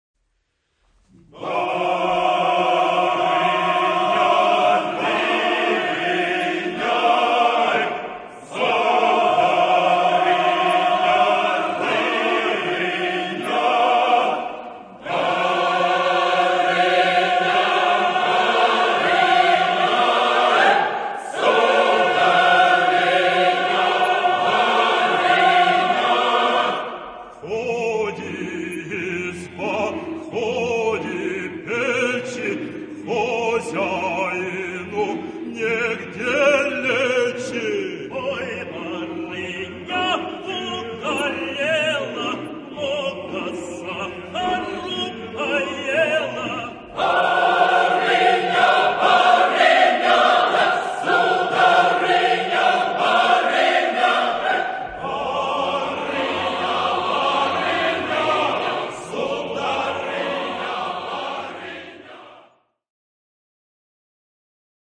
SSAATTBB (8 gemischter Chor Stimmen) ; Partitur mit unterlegtem Klavierauszug für Probezwecke.
Genre-Stil-Form: Liedsatz ; Volkslied ; weltlich Charakter des Stückes: humorvoll ; unbeschwert
Solisten: Tenor (1) / Bass (1) (2 Solist(en))
Tonart(en): B-dur